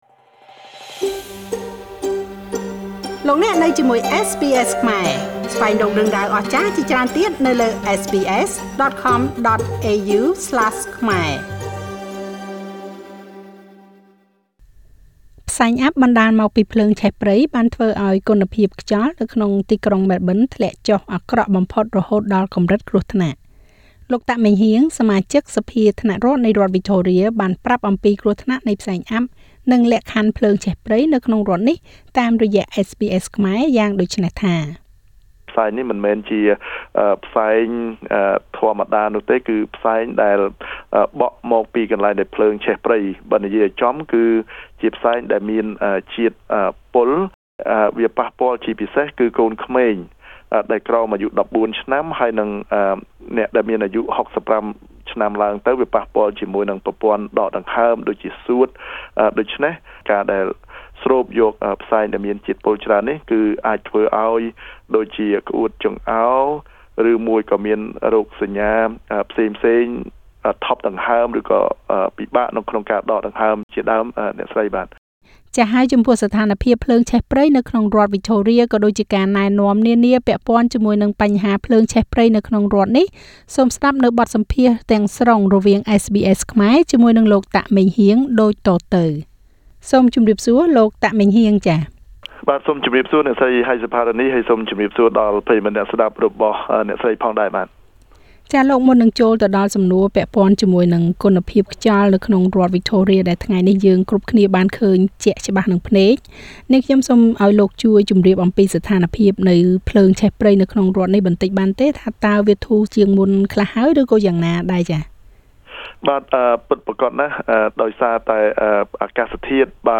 ផ្សែងអ័ព្ទបណ្តាលមកពីភ្លើងឆេះព្រៃ បានធ្វើឲ្យគុណភាពខ្យល់នៅទីក្រុងម៉ែលប៊ិនធ្លាក់អាក្រក់បំផុត រហូតដល់កម្រិតគ្រោះថ្នាក់។ លោក តាកម៉េងហ៊ាង សមាជិកសភាថ្នាក់រដ្ឋនៃរដ្ឋវិចថូរៀ ប្រាប់ពីគ្រោះថ្នាក់នៃផ្សែងអ័ព្ទ និងលក្ខខណ្ឌភ្លើងឆេះព្រៃនៅក្នុងរដ្ឋនេះ។